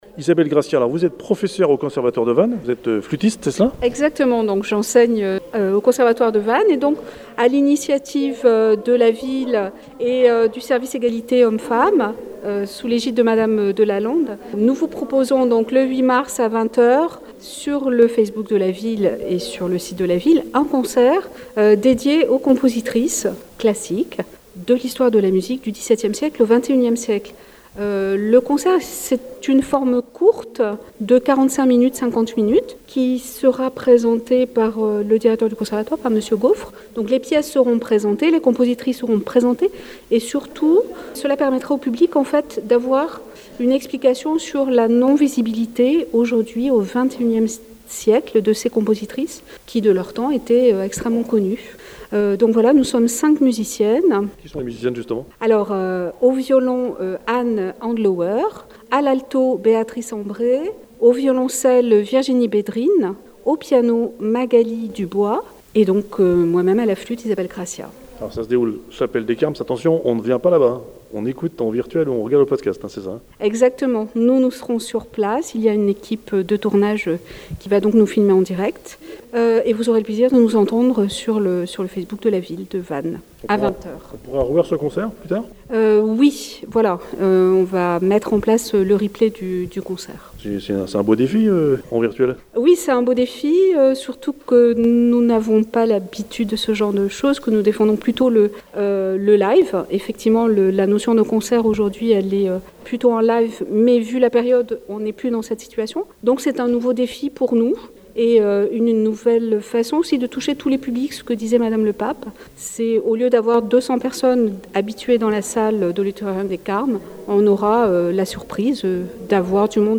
Interview Hortense Le Pape – Maire Adjointe à Vannes